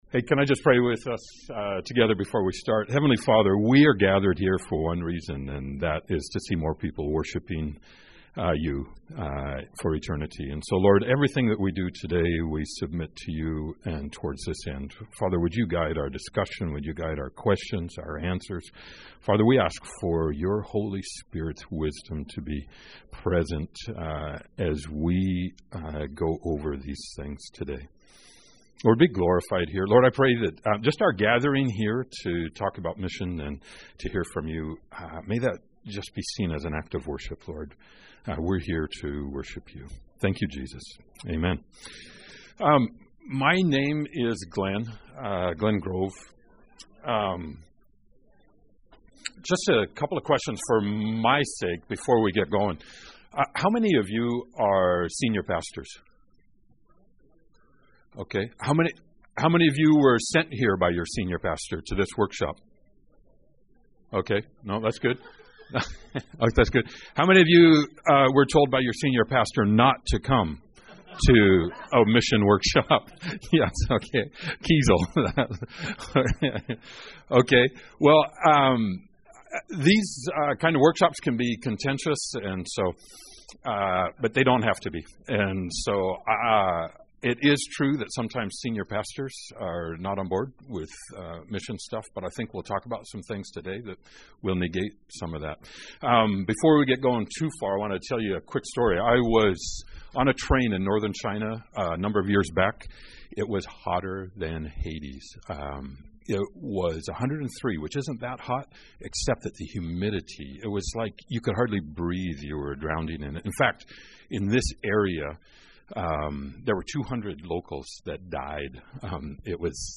Workshop: Making a global difference through the local church